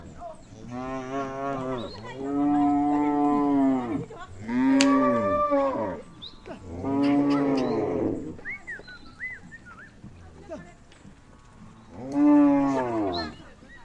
Netball Net
描述：Recording of a netball going through the net
标签： Sports Netball Net Field recording
声道立体声